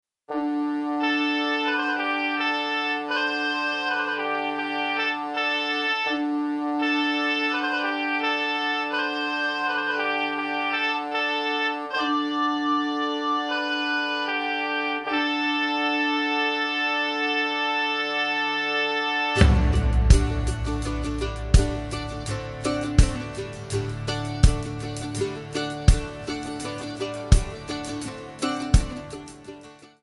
MPEG 1 Layer 3 (Stereo)
Backing track Karaoke
Country, 1980s